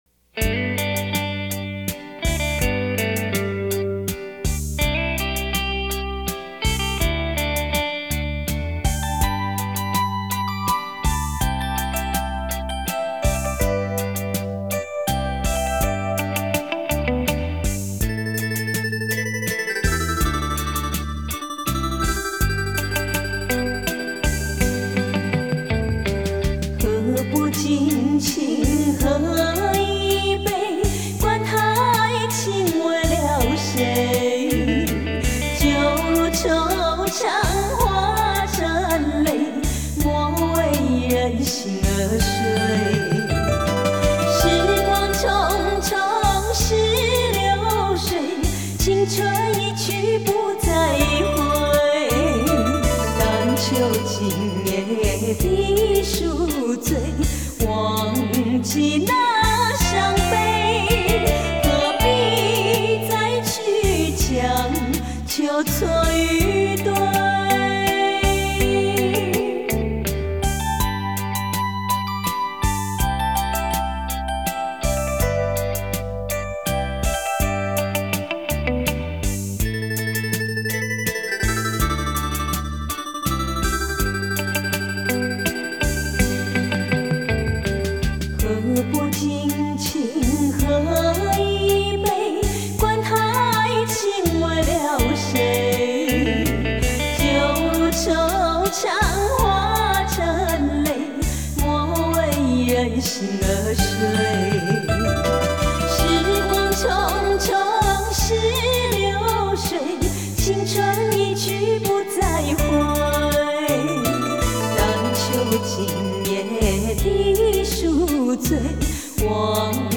甜歌杯中酒，甘醇芬芳沁人心脾，动人的舞三步，養耳又養心···